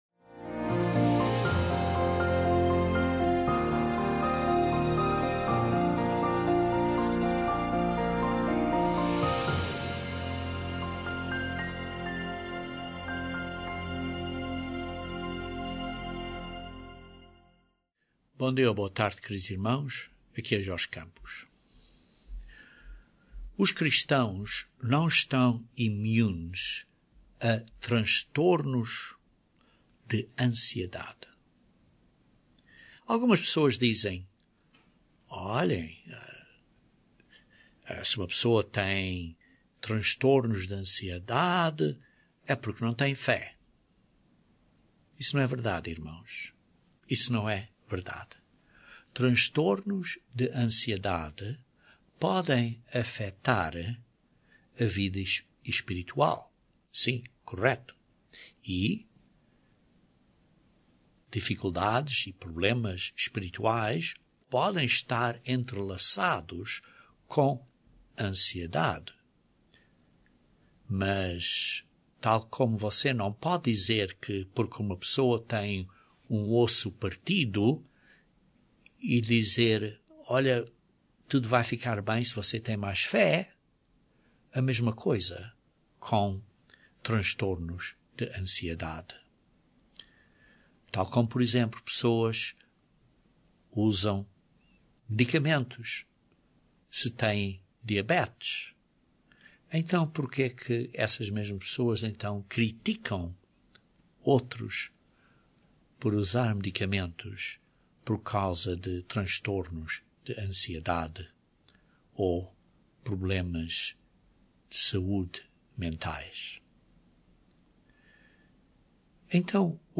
Cristãos não estão imunes a transtornos de ansiedade. Este sermão identifica algumas dicas Bíblicas para o ajudar se tem transtornos de ansiedade, ou para você ajudar a outros que tenham esse desafio.